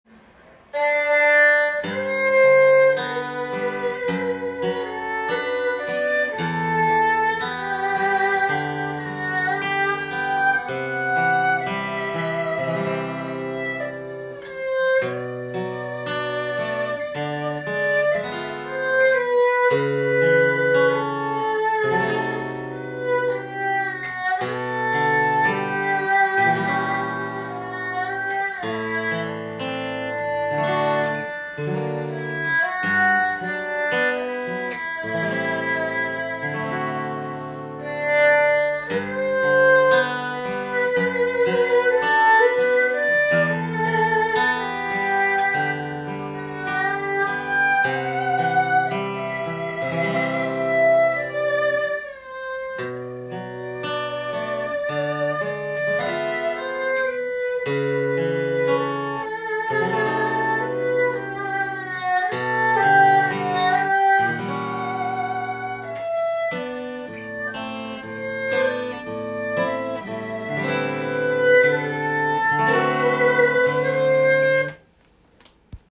譜面通り弾いてみました
主にローポジションでのアルペジオになりますが